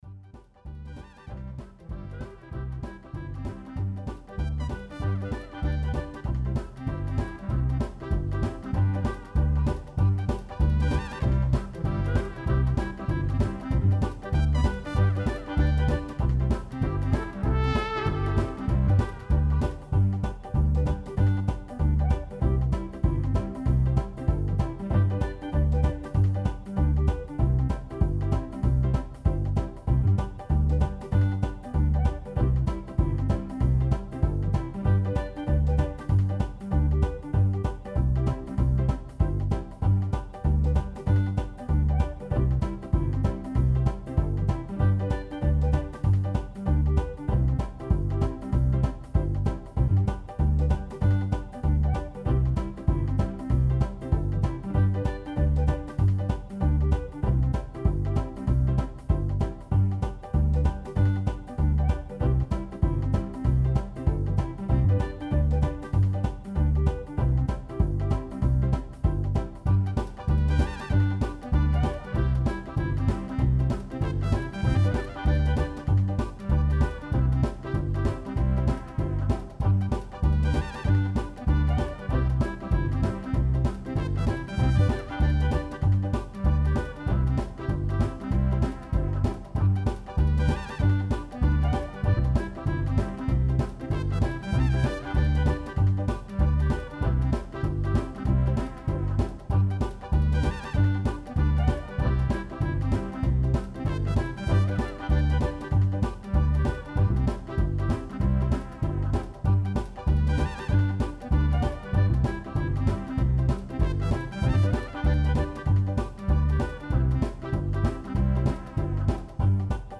Ambient, Jazz, Swing, Klassik und Experimental.